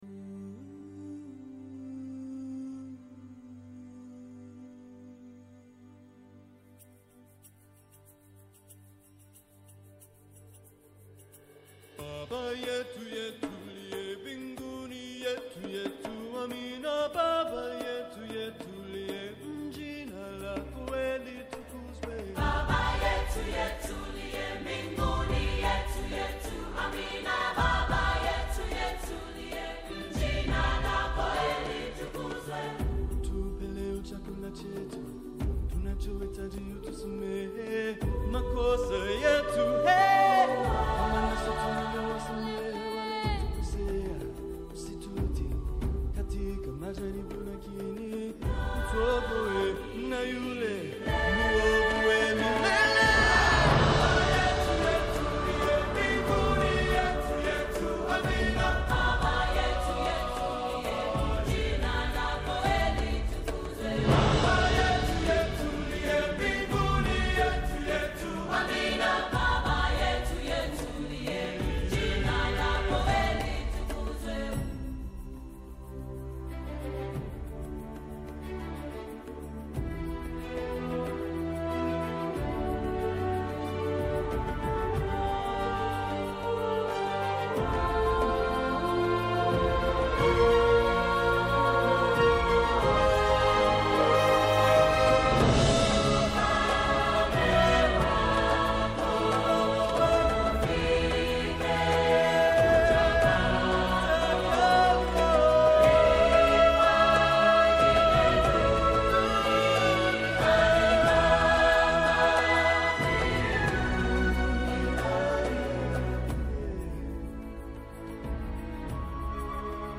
Επίσης, ο Γιώργος Σταθάκης, πρώην υπουργός, υποψήφιος βουλευτής ΣΥΡΙΖΑ στα Χανιά, καθηγητής Πολιτικής Οικονομίας στο Πανεπιστήμιο Κρήτης σε μια συζήτηση από την κατάρρευση δύο τραπεζών στις ΗΠΑ ως τις κυβερνητικές επιλογές στην αγορά της ενέργειας και γενικότερα την οικονομία και από την κοινοβουλευτική συζήτηση της σιδηροδρομικής τραγωδίας μέχρι τις επιπτώσεις της σε κυβέρνηση και αντιπολίτευση ενόψει εκλογών.